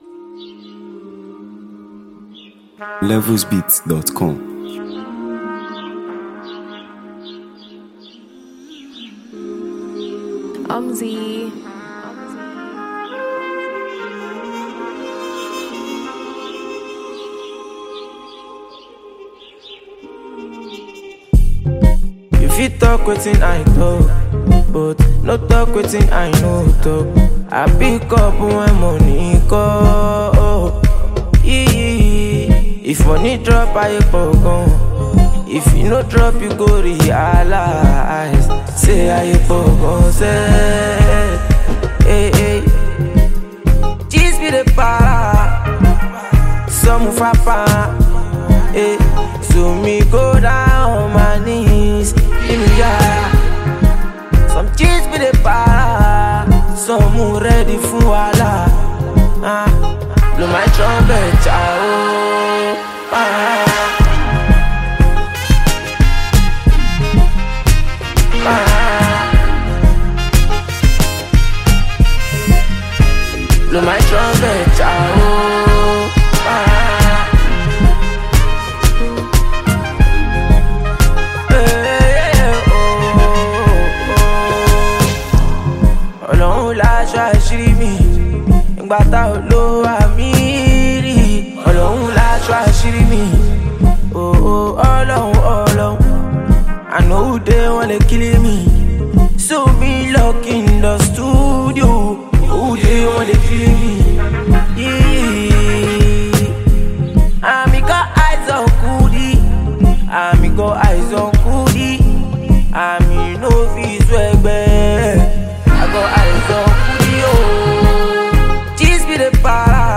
If you’re a fan of Afrobeat